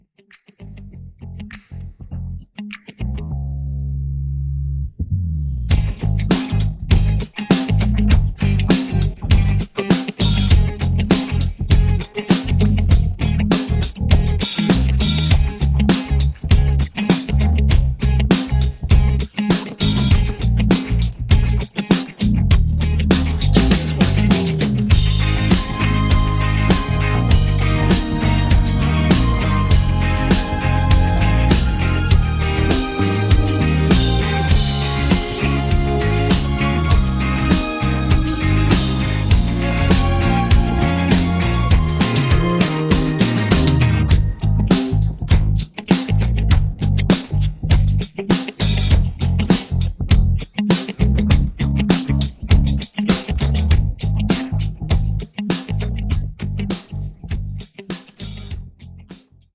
음정 -1키 3:40
장르 가요 구분 Voice Cut